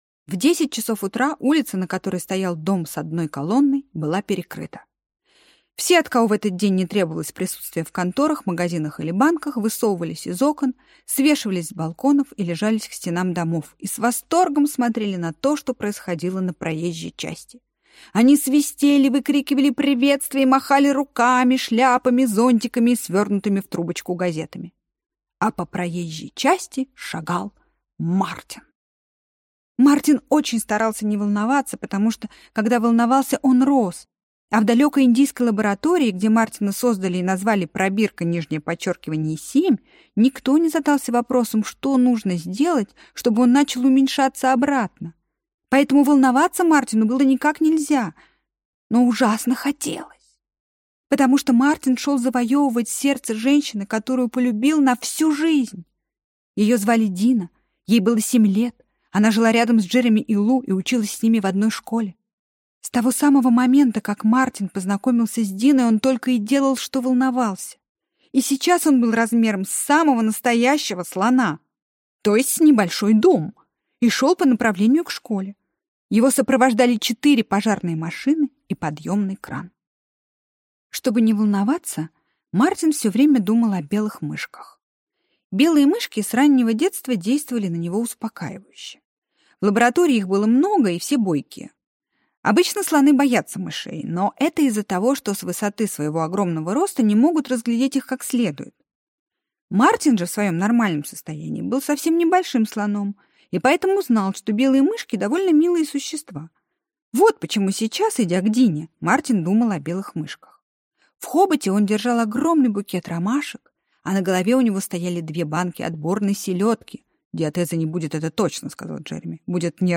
Мартин не плачет (слушать аудиокнигу бесплатно) - автор Линор Горалик